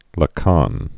(lə-kän, lä-käɴ), Jacques-Marie Émile 1901-1981.